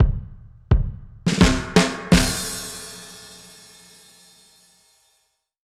Index of /musicradar/dub-drums-samples/85bpm
Db_DrumsA_Wet_85_04.wav